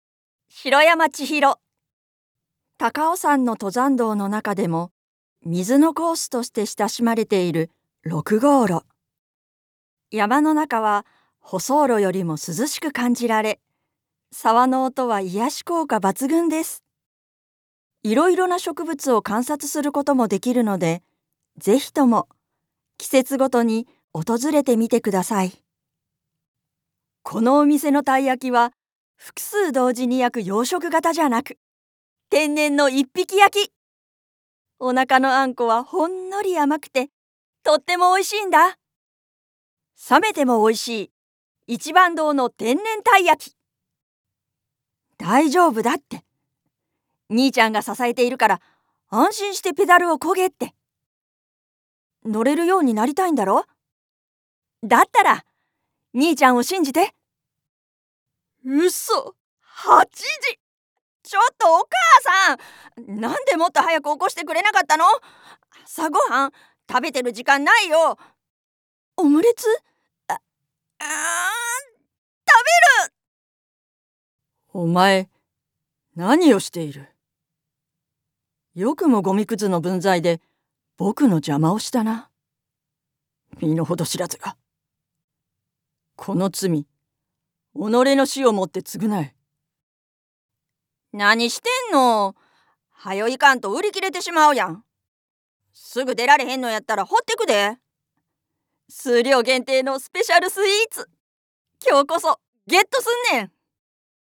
声のタイプ：元気な明るい声
サンプルボイスの視聴
キャラクター 少年・おばちゃん